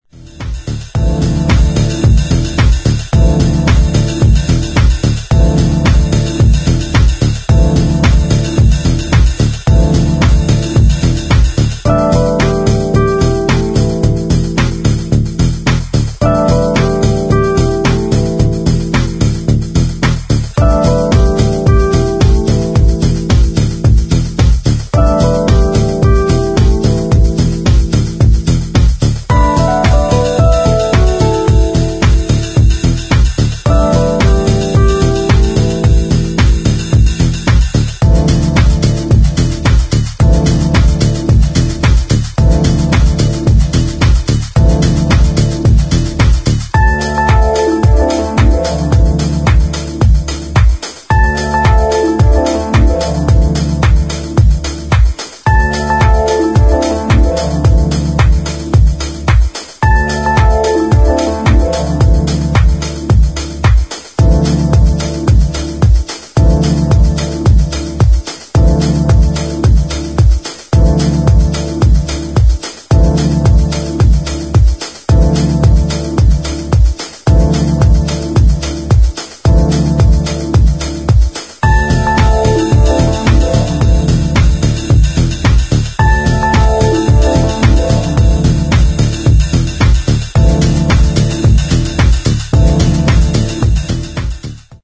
[ HOUSE / TECHNO ]